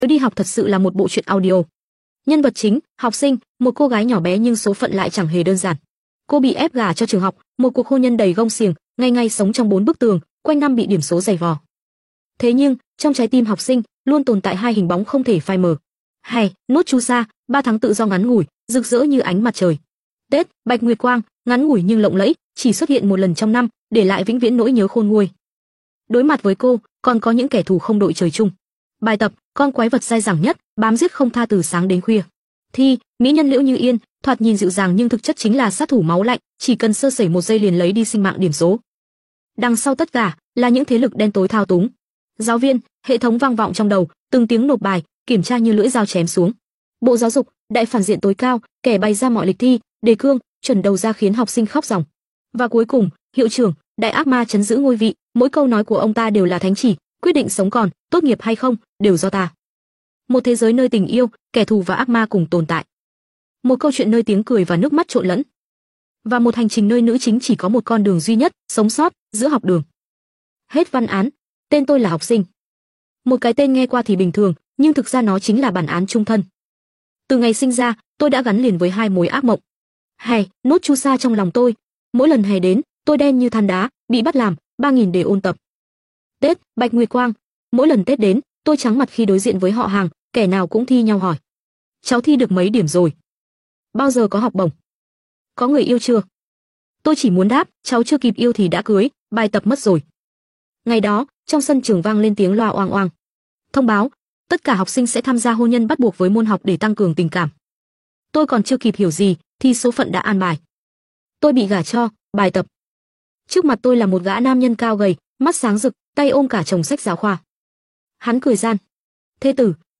truyện audio